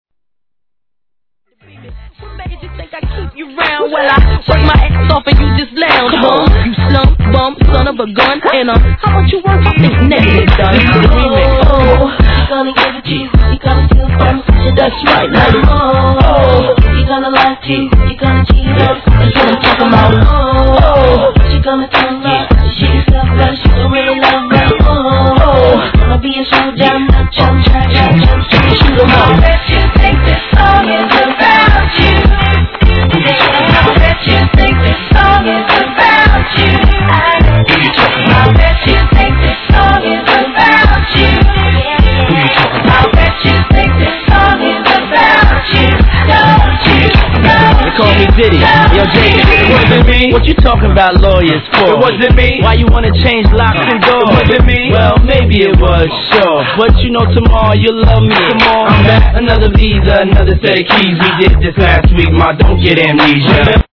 HIP HOP/R&B
年代を問わず、往年の名曲をDJのためにREMIXされたDJ USEコンピ!!